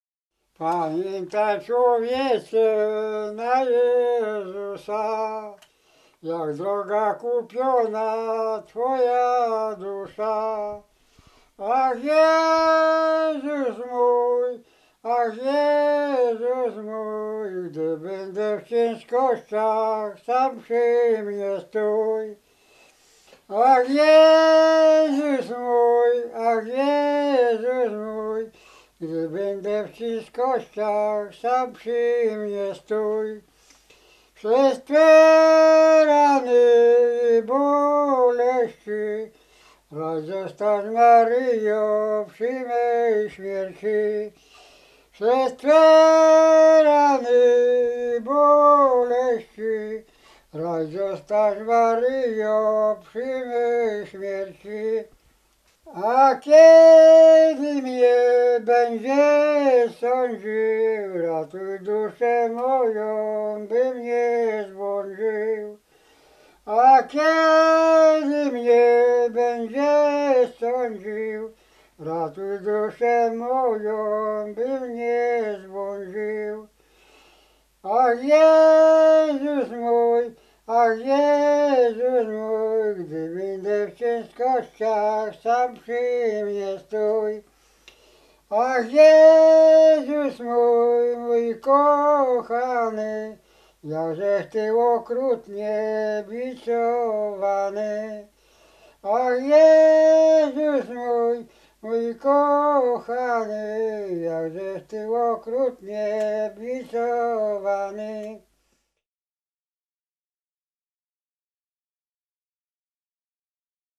Ziemia Radomska
Pogrzebowa
pogrzebowe nabożne katolickie